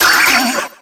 Cri de Grindur dans Pokémon X et Y.